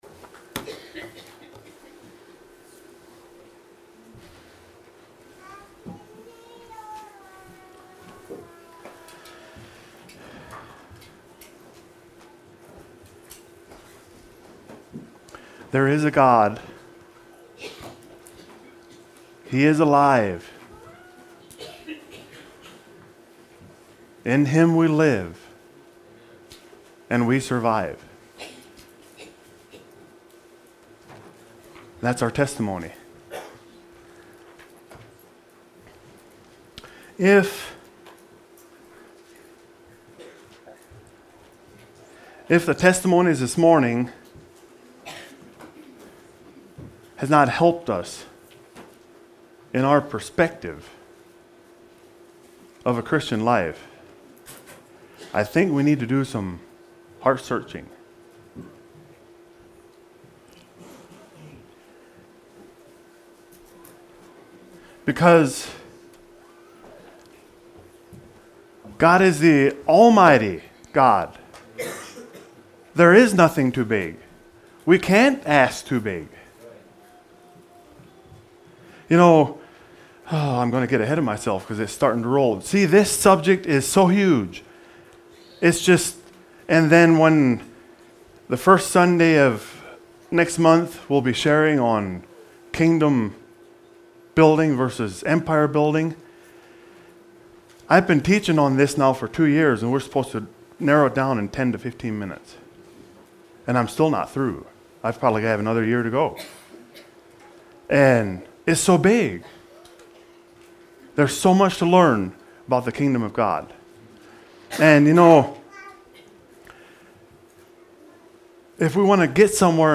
Sermons Sermon on the Mount